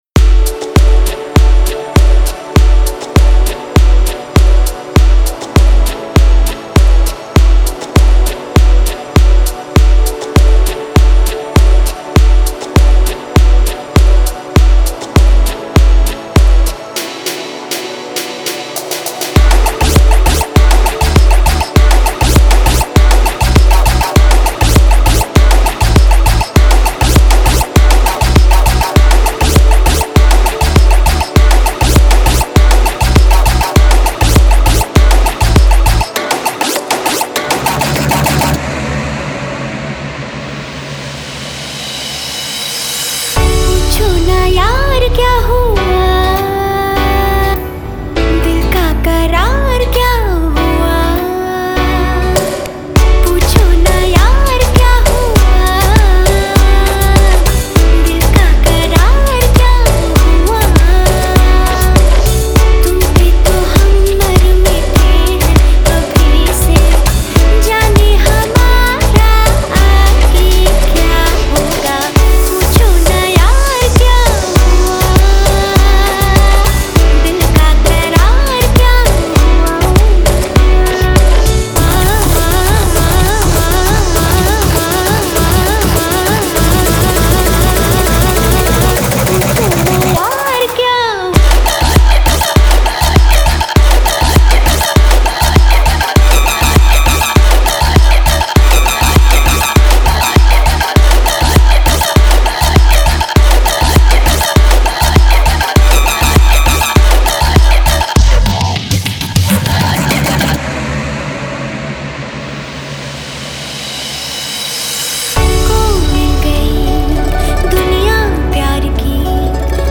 Bollywood DJ Remix
Bollywood DJ Remix Songs